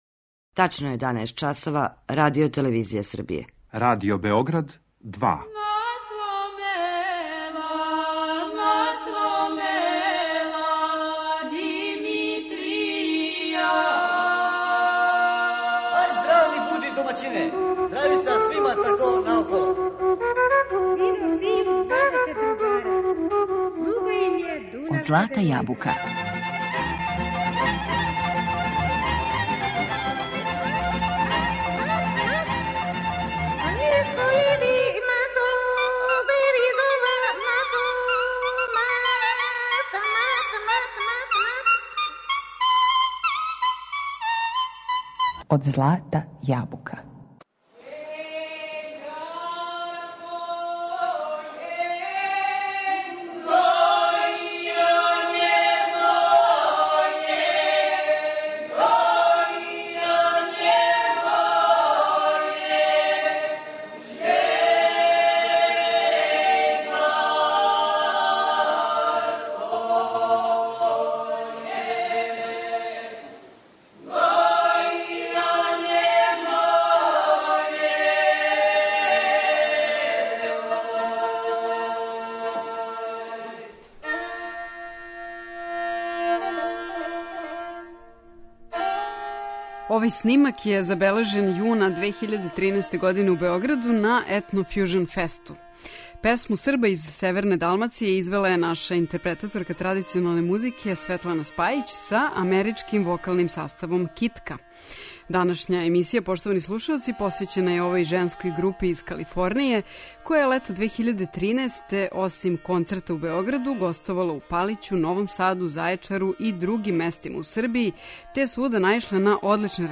Китка је женска певачка група из Калифорније.